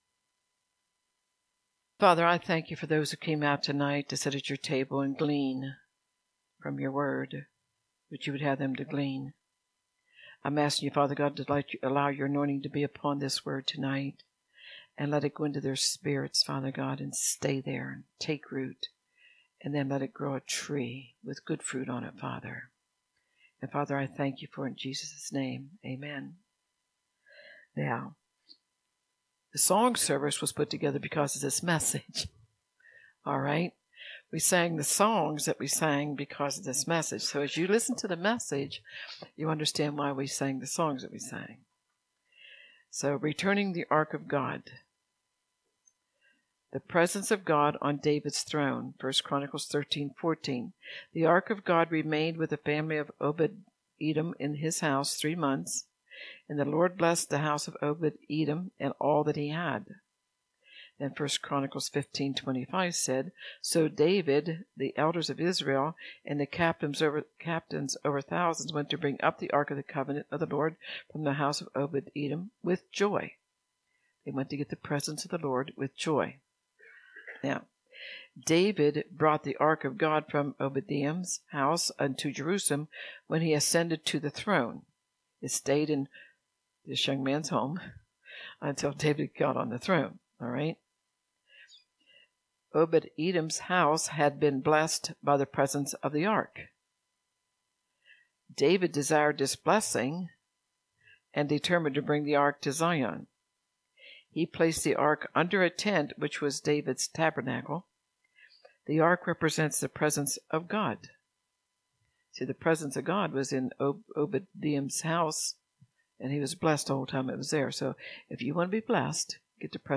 In His Presence Revival